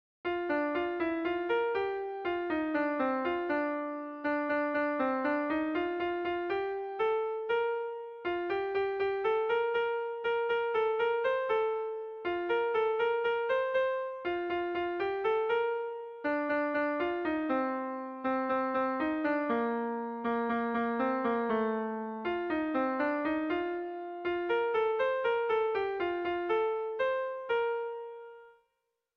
Irrizkoa
Hamalaukoa, txikiaren moldekoa, 9 puntuz (hg) / Bederatzi puntukoa, txikiaren moldekoa (ip)